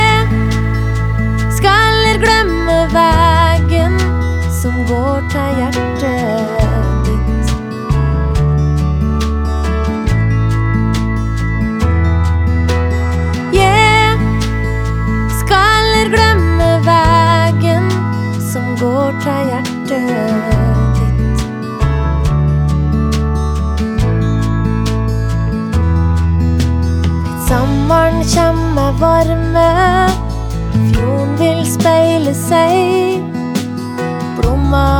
Жанр: Поп музыка / Рок / Альтернатива / Кантри